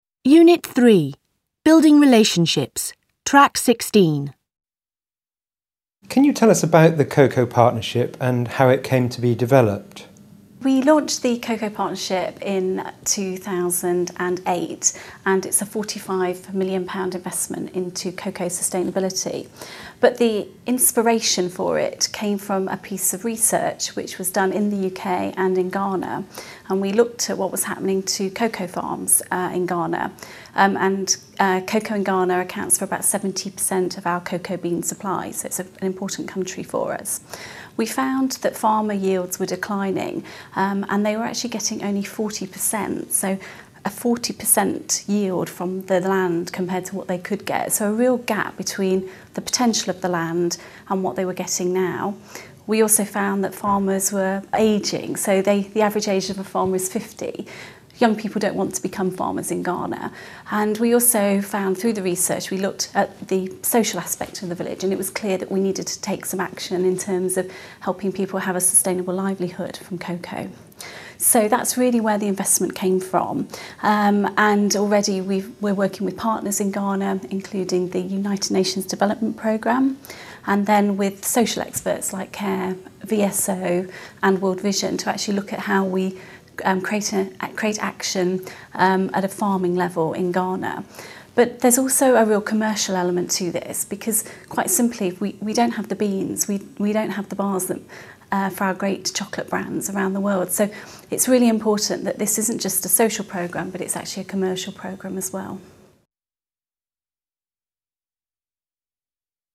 Słuchanie – dwa lub trzy teksty do słuchania (monolog i/lub dialog w obszarze zagadnień zgodnych z programem nauczania / ESOKJ).
Listening comprehension 2.mp3